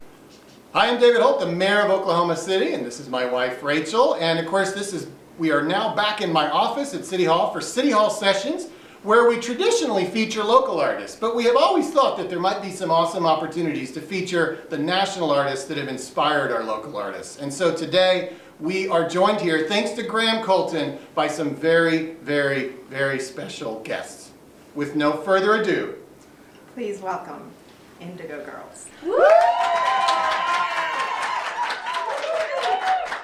lifeblood: bootlegs: 2020-02-26: the mayor's office - oklahoma city, oklahoma (city hall sessions concert series)
(captured from a youtube video)
01. introduction by mayor david holt (0:33)